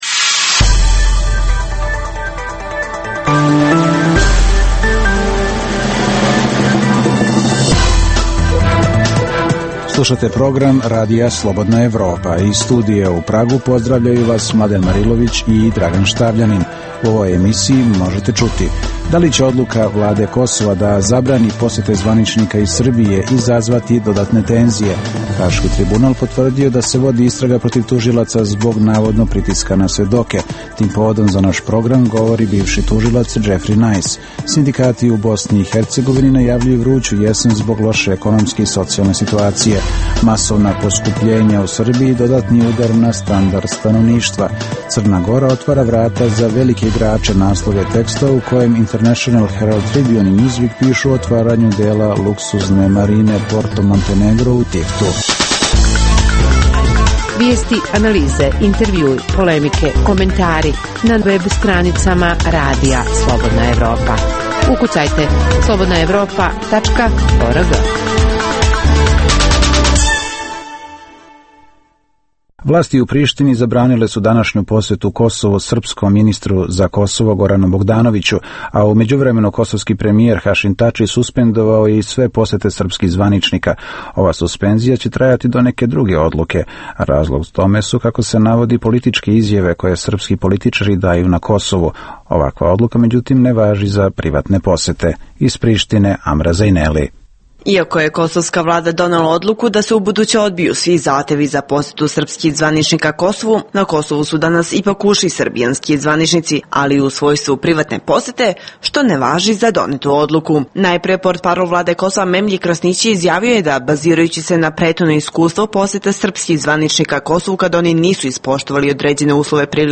- Reportaža iz Ruda iz koga, pre svega mladi, i dalje odlaze trbuhom za kruhom zbog besperktivnosti.